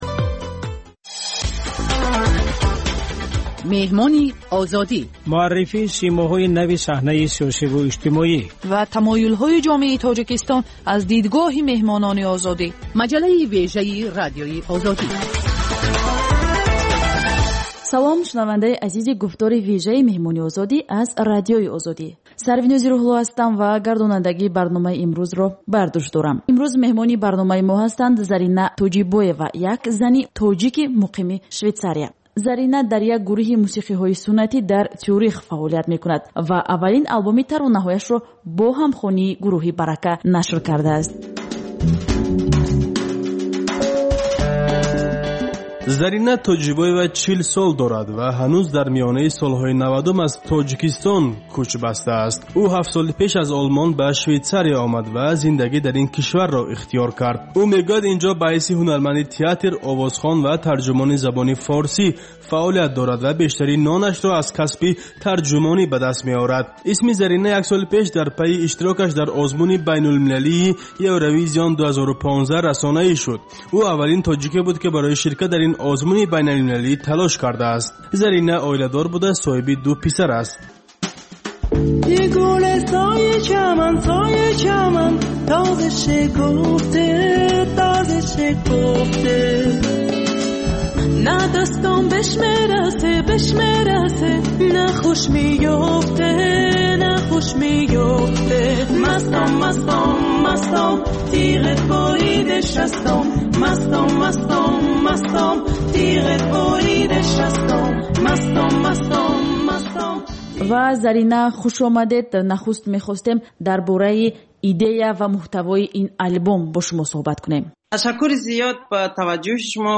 Гуфтугӯи ошкоро бо шахсони саршинос ва мӯътабари Тоҷикистон, сиёсатмадорону ҷомеашиносон, ҳунармандону фарҳангиён